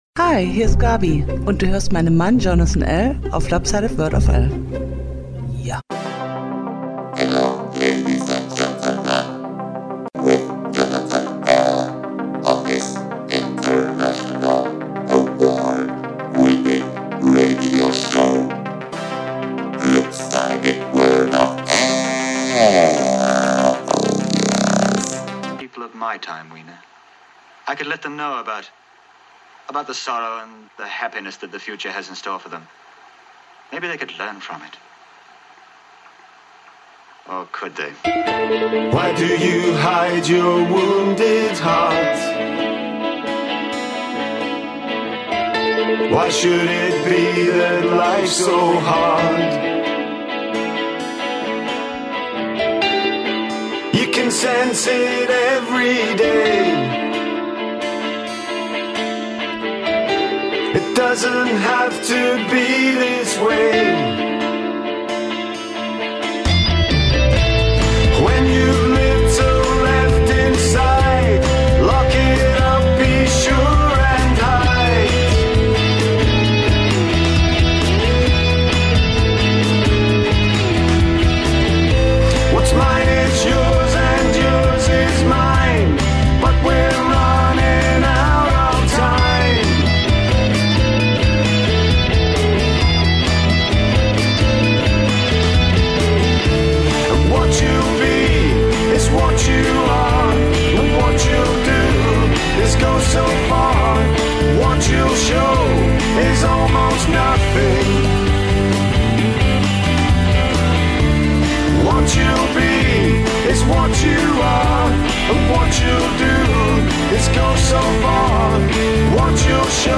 New indie rock from around the world